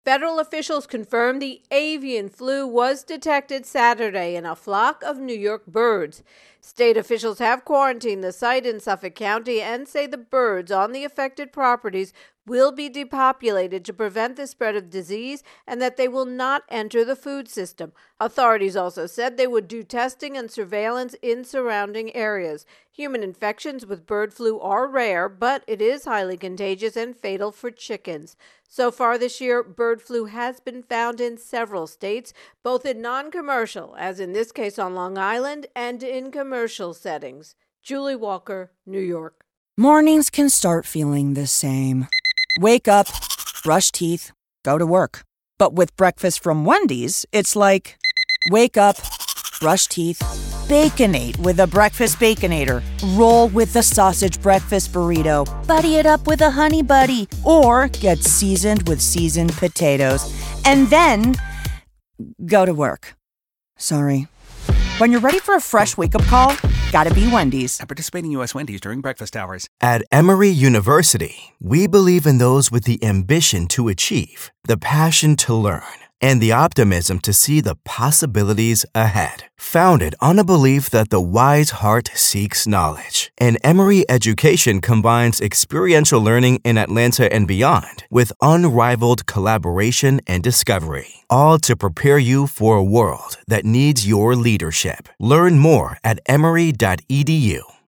Bird Flu New York intro and voicer